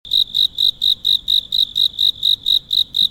蟋蟀 | 健康成长
xishuai-sound.mp3